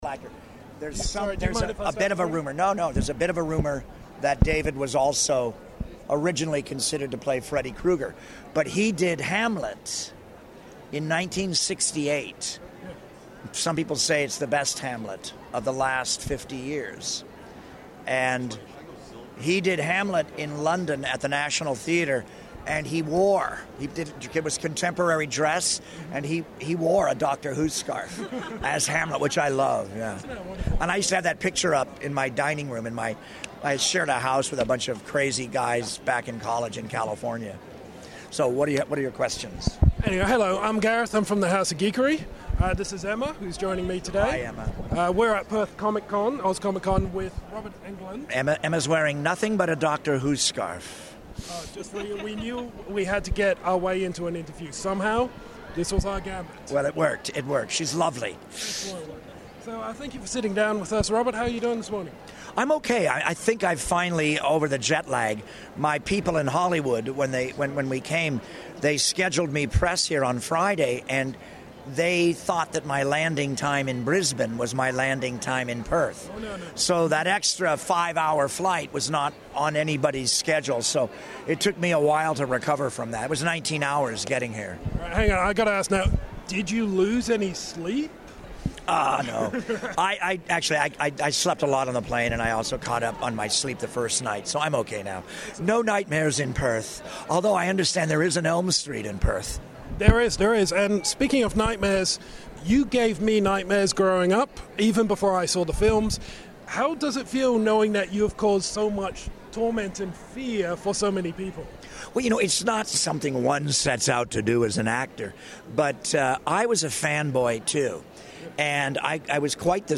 Exclusive Interview with Robert Englund!
We got the chance to sit down with the man behind the glove for a chat at Oz ComicCon.
Robert is a very chatty guy, and had already started into a great story about fellow actor David Warner before we even started recording.